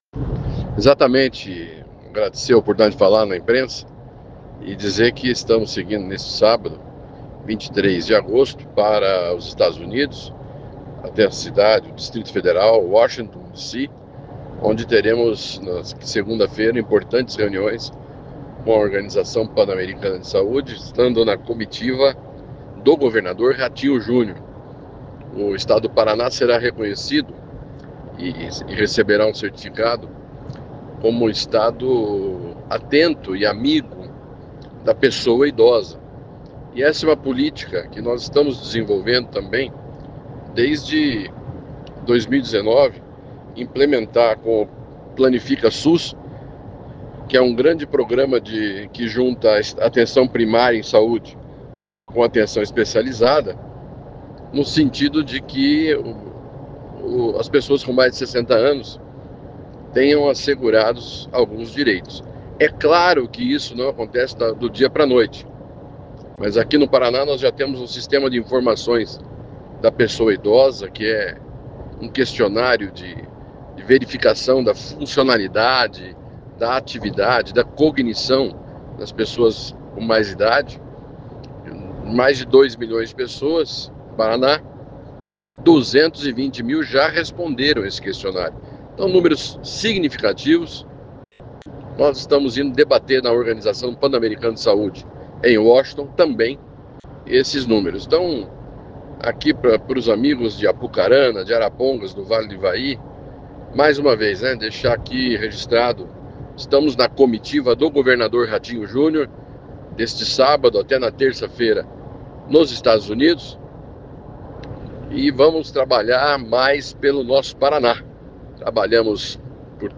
O secretário concedeu entrevista à imprensa antes do embarque. Segundo ele, há uma busca pela conquista do Condomínio do Idoso de Apucarana.
Confira a entrevista com o Secretário de Saúde, Beto Preto.